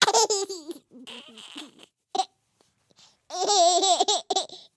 tickle2.ogg